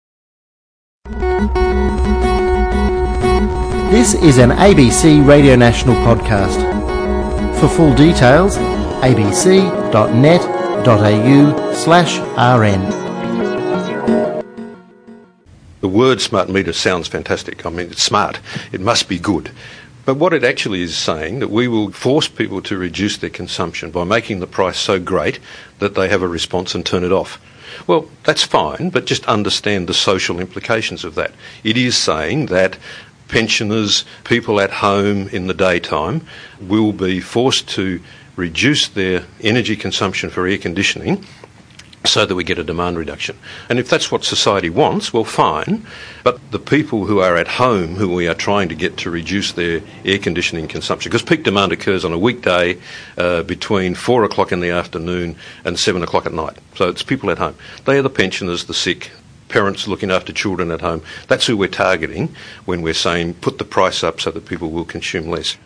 A recent reminder of these facts came in the form of a November 2016 radio program from Australia, “Electric Shocks.”